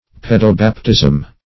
Search Result for " pedobaptism" : The Collaborative International Dictionary of English v.0.48: Pedobaptism \Pe`do*bap"tism\, n. [Gr. pai^s, paido`s, a child + E. baptism.] The baptism of infants or of small children.